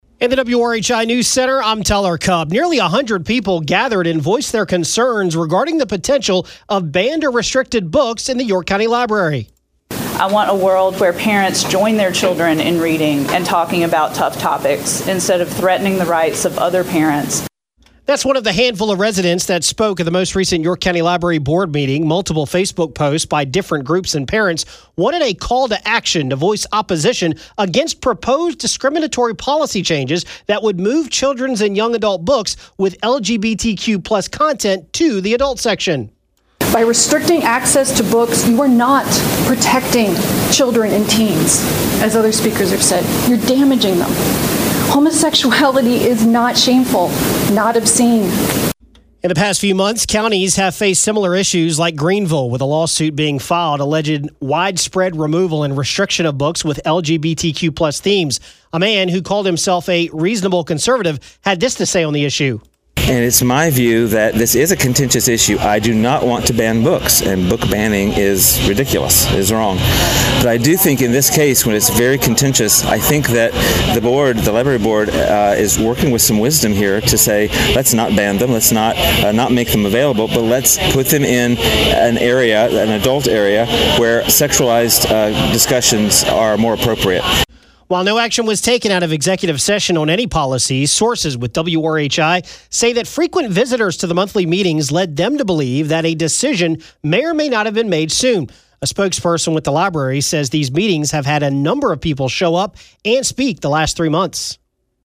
AUDIO: People speak out at the York County Library Board meeting today regarding potential policy changes on books being re-located and restrictions